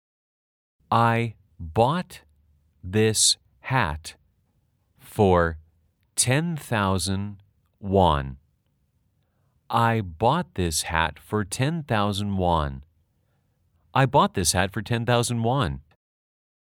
/ 아이 보옷 디쓰 / 햇 퍼 / 텐 따우전 워언 /
아주 천천히-천천히-빠르게 3회 반복 연습하세요.